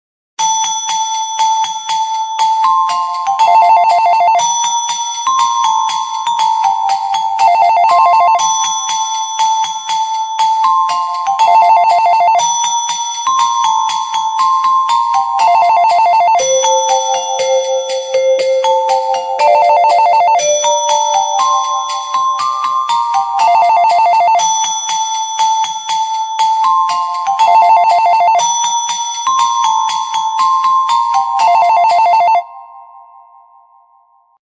Новогодние рингтоны